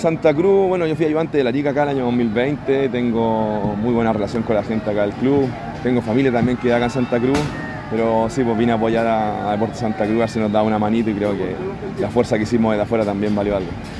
En conversación exclusiva con Primera B Chile